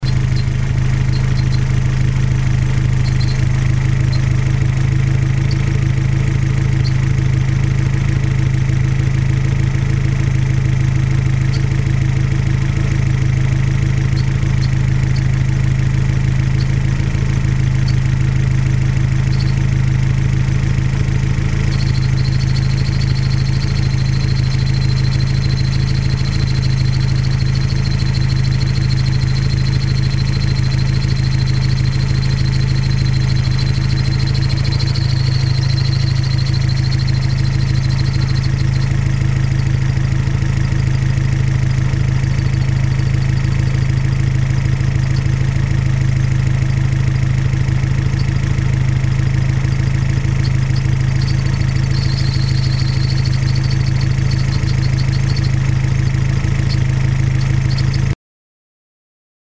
Der Motor machte bereits nach dem ersten Lauf ein "zwitscherndes" Geräusch.
Entweder die Geräusche kommen von der Einspritzanlage oder vom gerade erst erneuerten Zahnriementrieb / Wasserpumpe.
Geräusch zum Anhören (mp3, 1.8MB)
noise.mp3